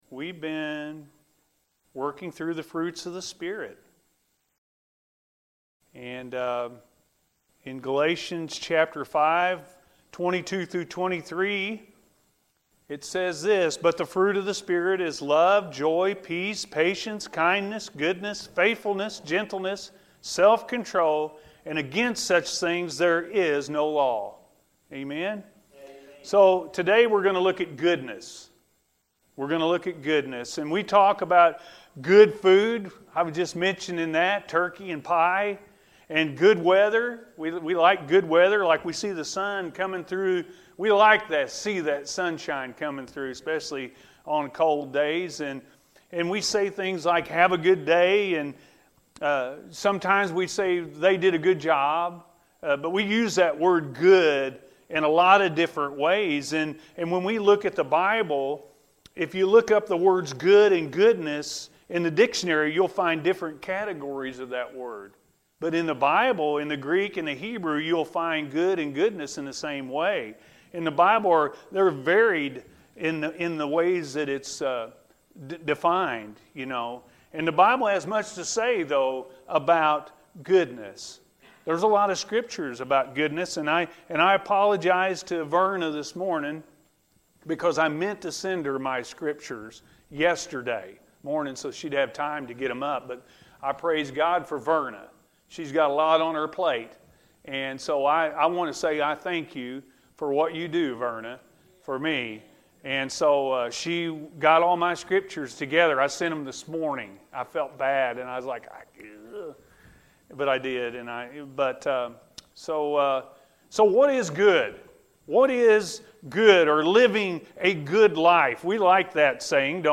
Goodness-A.M. Service – Anna First Church of the Nazarene